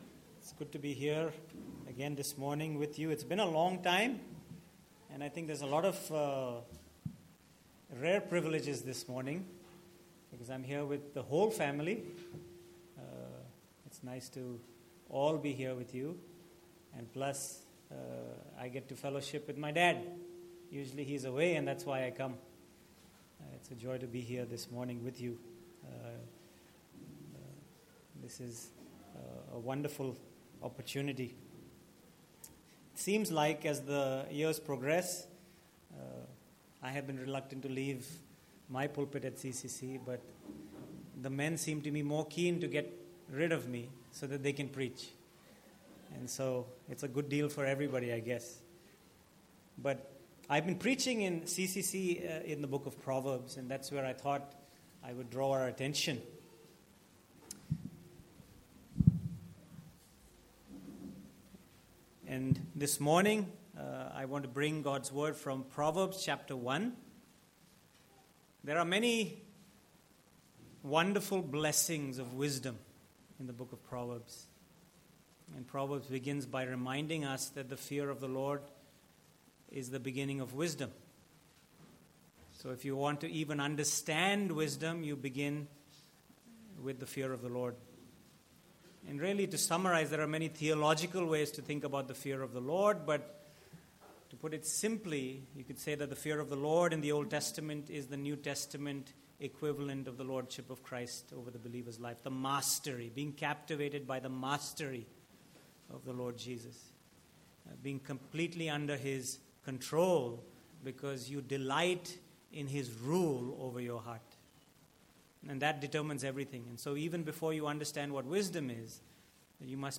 Passage: Proverbs 1:20-33 Service Type: Sunday Morning